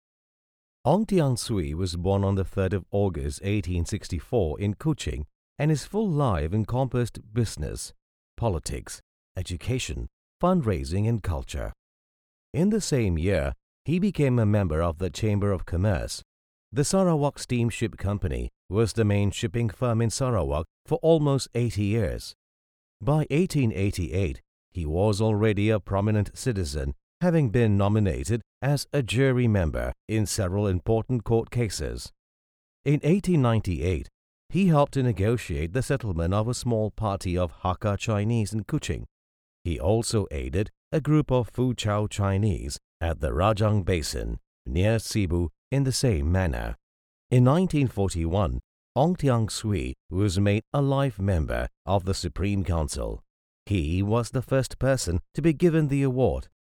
Masculino
Narration
Deep Matured voice who has won numerous awards such as the EFFIE awards in 2007.